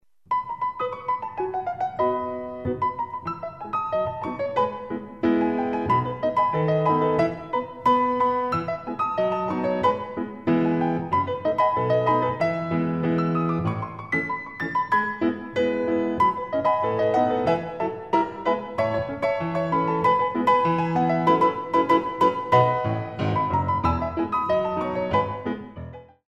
Compositions for Ballet Class
Some very Classical plus some jazz - ragtime rhythms
The CD is beautifully recorded on a Steinway piano.
Degages en l’air